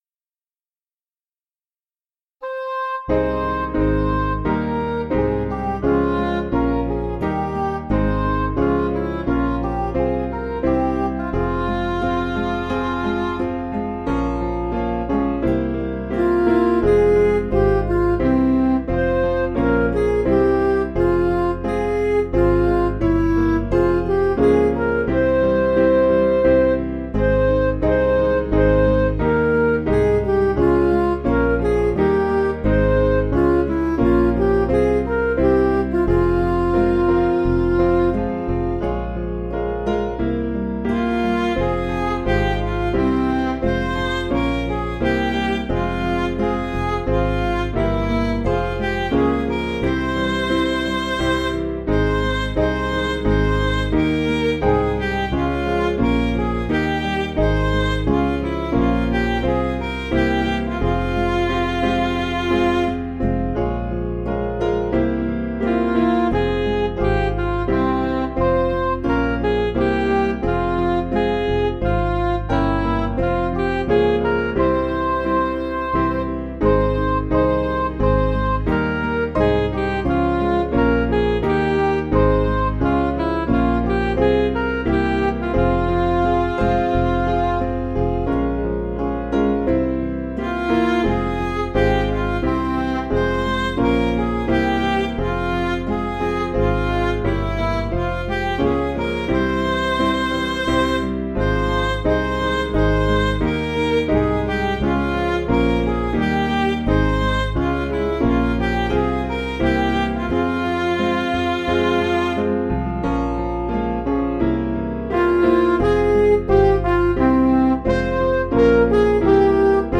Piano & Instrumental
(CM)   5/Fm